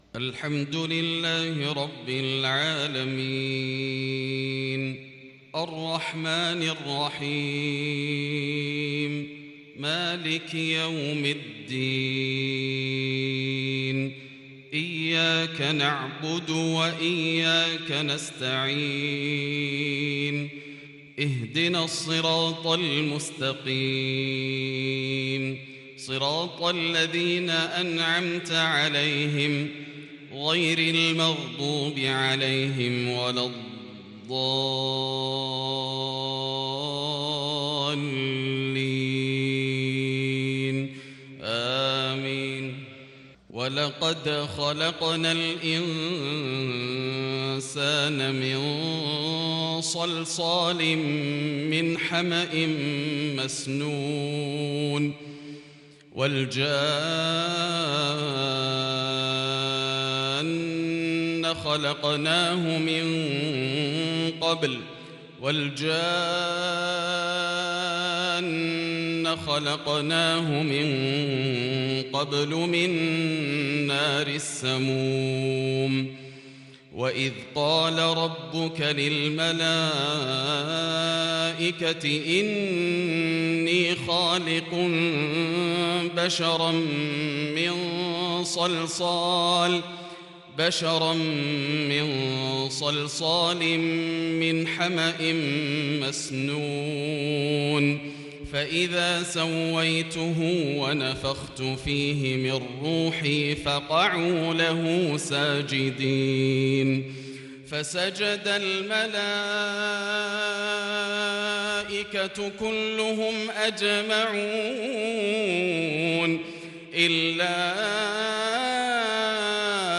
صلاة العشاء للشيخ ياسر الدوسري 29 شوال 1442 هـ
تِلَاوَات الْحَرَمَيْن .